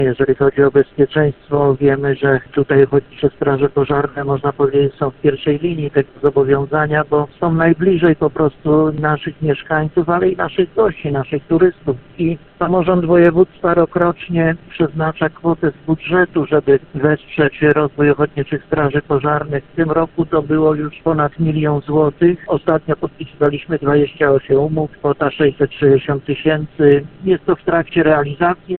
– Nieco ponad połowa z tego, to wsparcie zakupu samochodów ratowniczo-gaśniczych – informuje marszałek województwa, Gustaw Marek Brzezin.